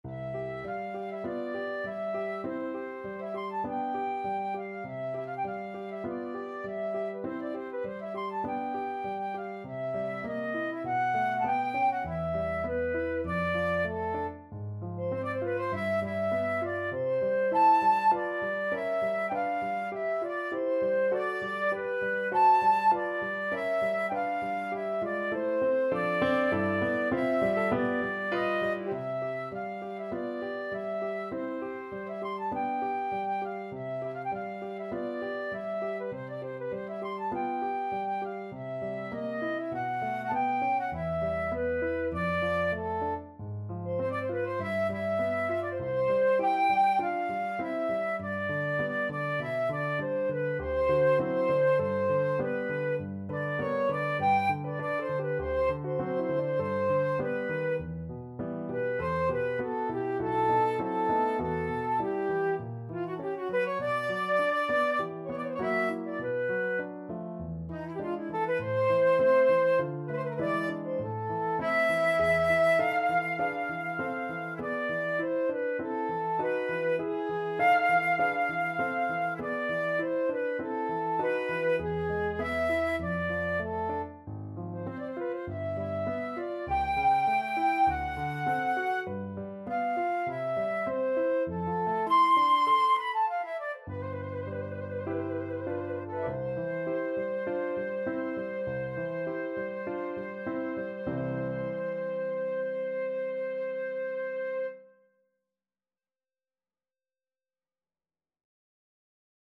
Flute
4/4 (View more 4/4 Music)
C major (Sounding Pitch) (View more C major Music for Flute )
Classical (View more Classical Flute Music)